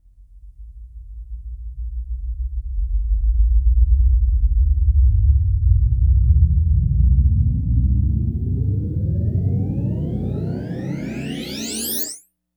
RUMBLER.wav